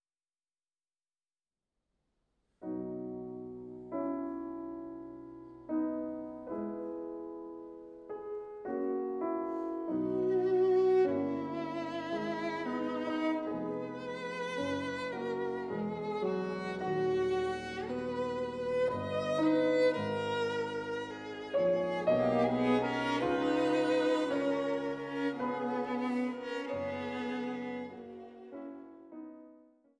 Violine
Klavier
im Studio der Musikhochschule aufgenommen